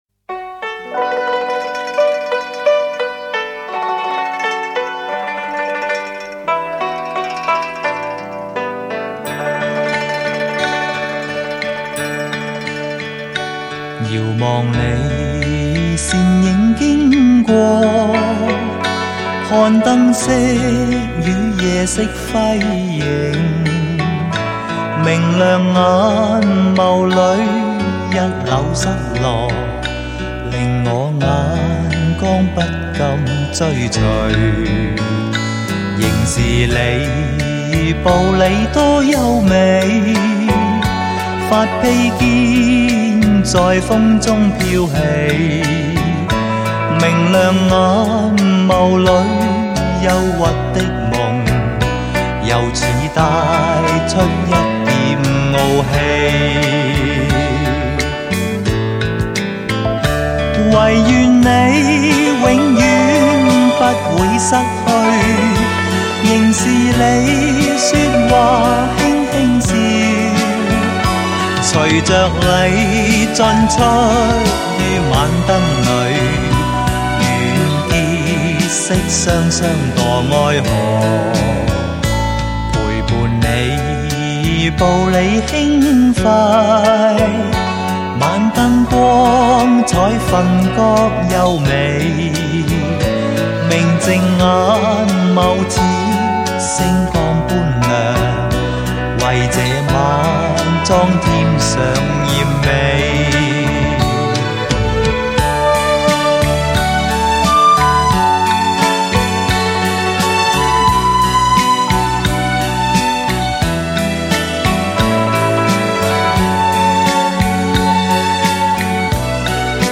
HI-FI顶级人声发烧天碟